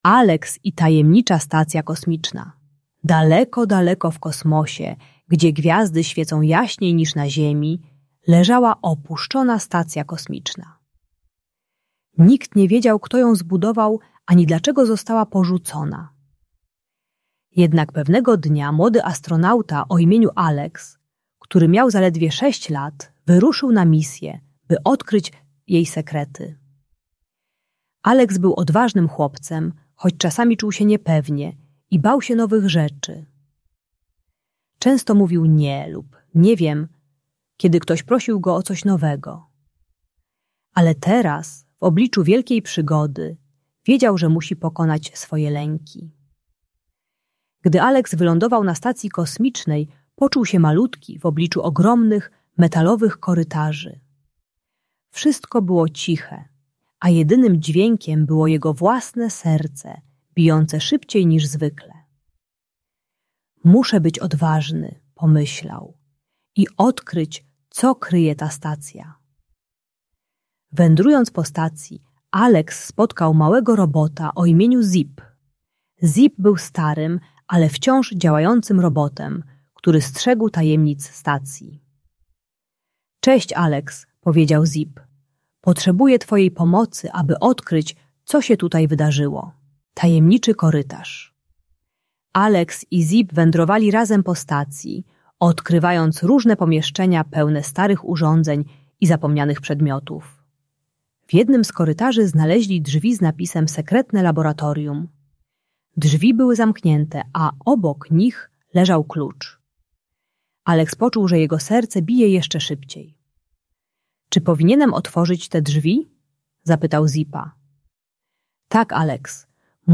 Alex i Tajemnicza Stacja Kosmiczna - Lęk wycofanie | Audiobajka